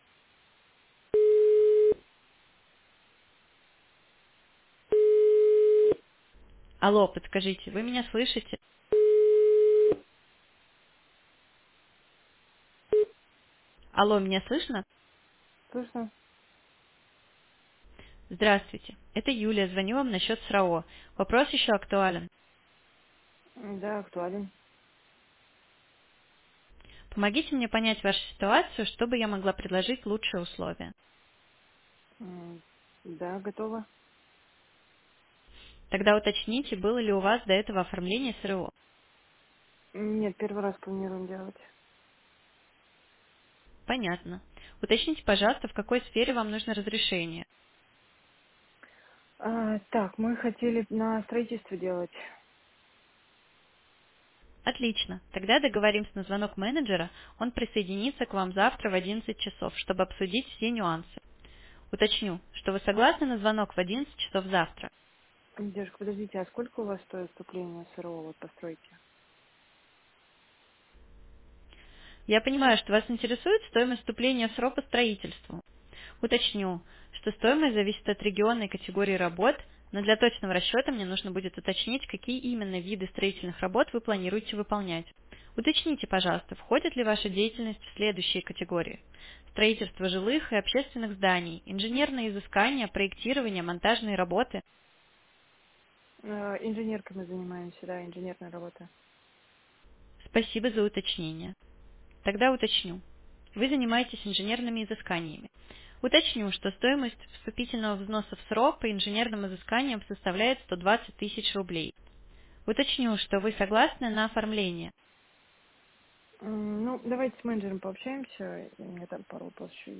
“AI-звонок”
• Очень реалистичный голос
голос Юлия - квалификация по СРО